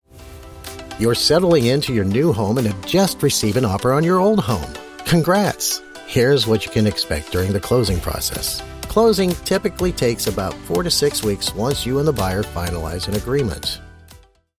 Male
Adult (30-50), Older Sound (50+)
Explainer Videos
Explainer Video Voice Over